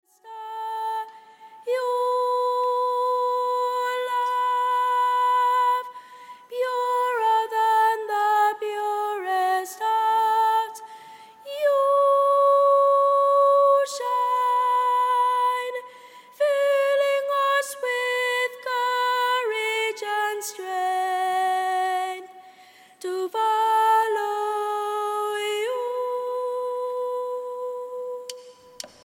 STYLE: Pop
Recorded live at St. Catherine's Church in Dublin, Ireland